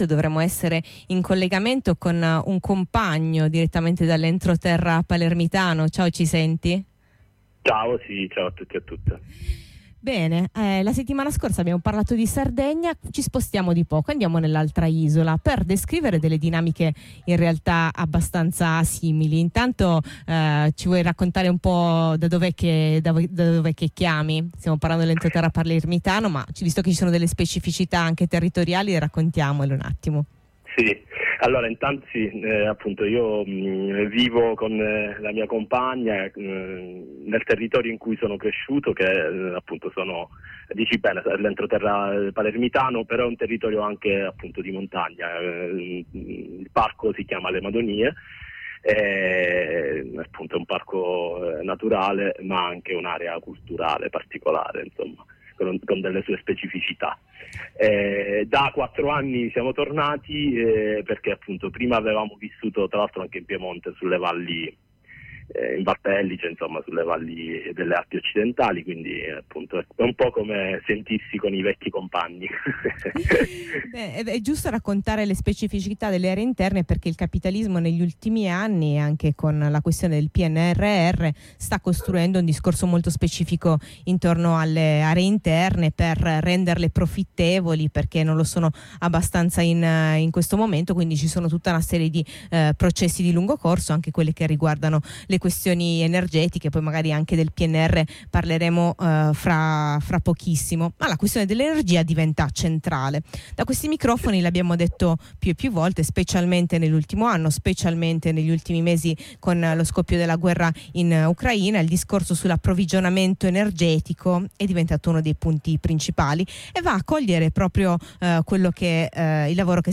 Ma le tecniche col marchio “verde” sono solo le ultime arrivate e sono complementari alle più consolidate forme di estrattivismo siciliano, tra mega-elettrodotti, economia del petrolio e servitù militari. Ai microfoni di Blackout una chiacchierata in diretta con un compagno dalle Madonie, nell’entroterra palermitano, sull’impatto nell’isola dei nuovi progetti strategici dello Stato: